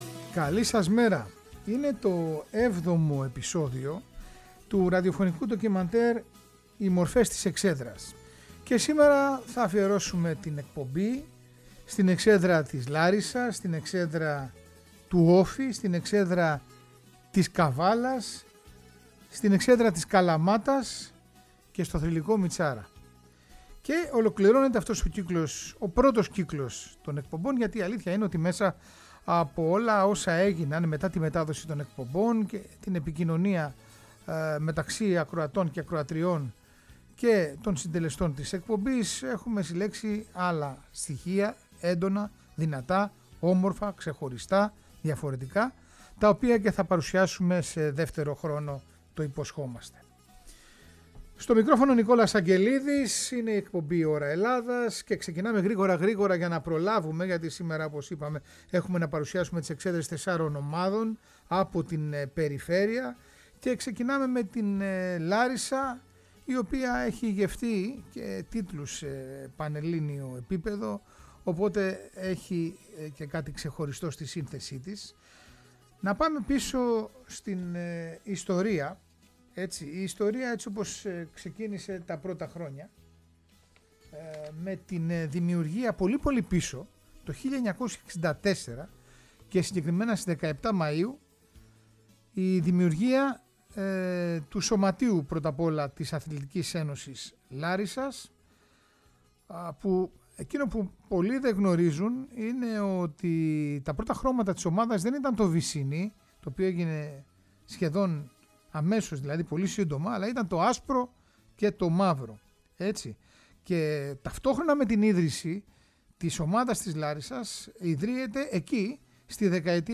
Ένα πρωτότυπο ραδιοφωνικό ντοκιμαντέρ για τους ήρωες που γέμιζαν τις Κυριακές μας στις εξέδρες.
Μπαίνουμε τις κερκίδες των ομάδων, αναζητώντας τα πρόσωπα που έγραψαν ιστορία. Συναντάμε θρυλικές φυσιογνωμίες της εποχής μέσα από άγνωστες – στο ευρύ κοινό – ιστορίες, σπάνιο υλικό και ηχητικά ντοκουμέντα που δεν έχουν ακουστεί ξανά στο ραδιόφωνο.